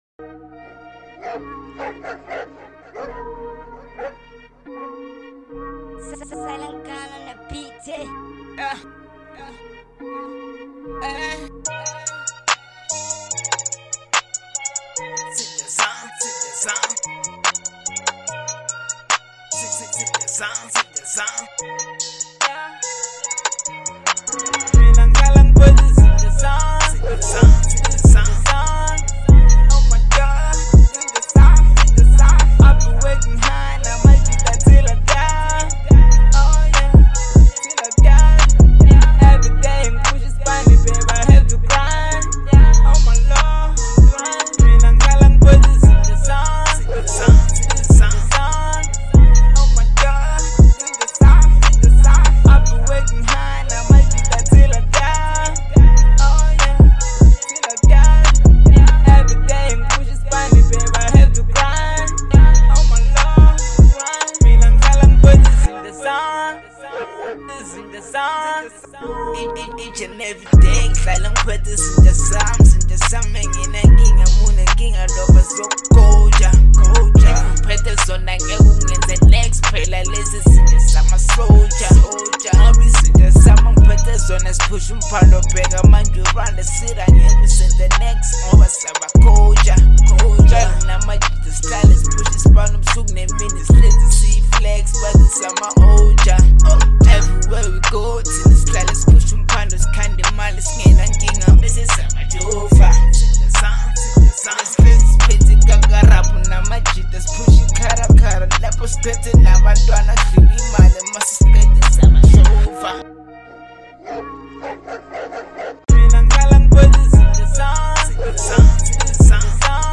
hip-hop
laced with dope lines and bars